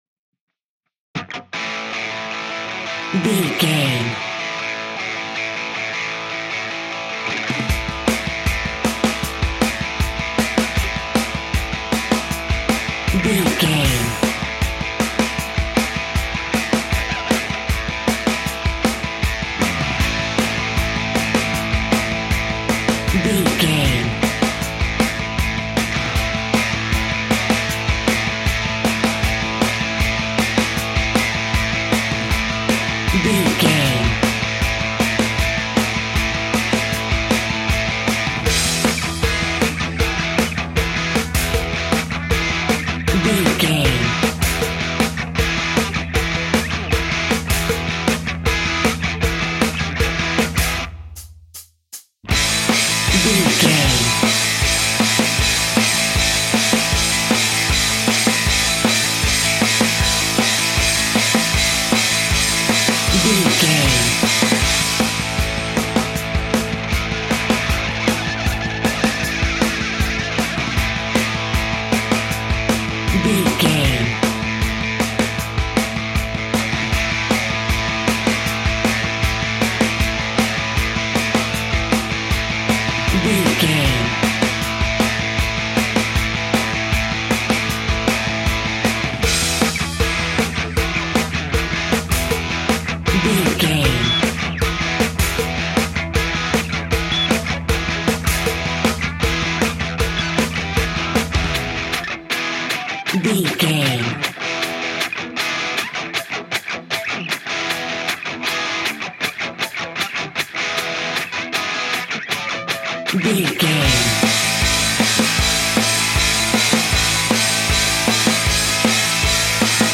Ionian/Major
Fast
energetic
driving
heavy
aggressive
electric guitar
bass guitar
drums
hard rock
heavy metal
blues rock
distortion
heavy drums
distorted guitars
hammond organ